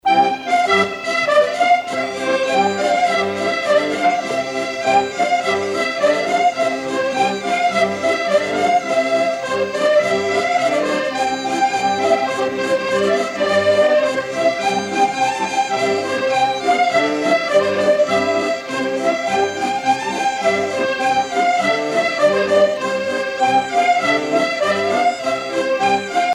danse : rondeau
Pièce musicale éditée